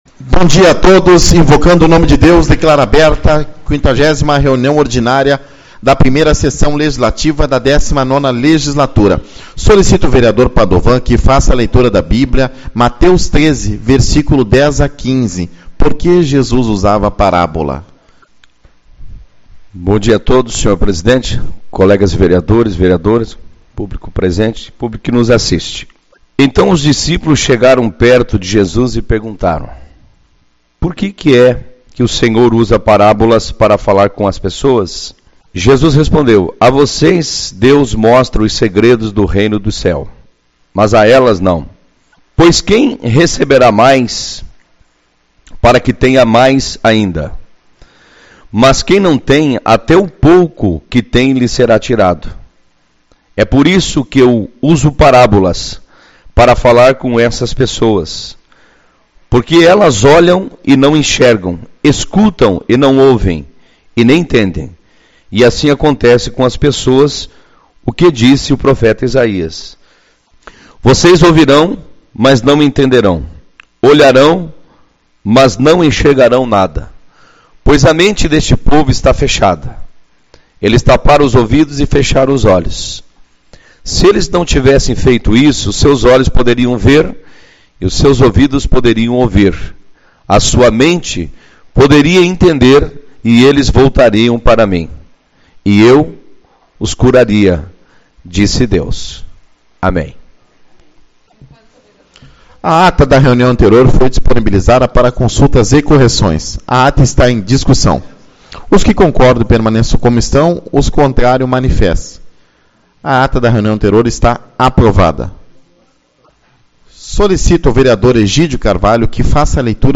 19/08 - Reunião Ordinária